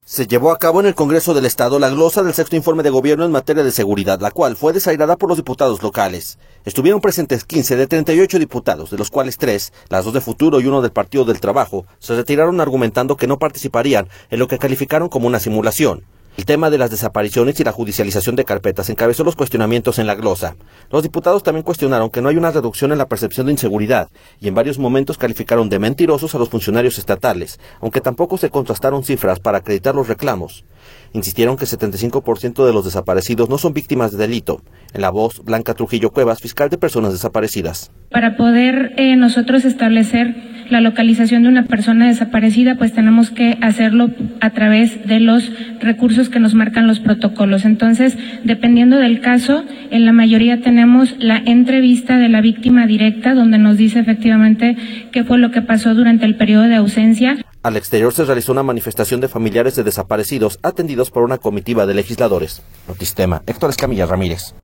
En la voz Blanca Trujillo Cuevas, fiscal de Personas Desaparecidas.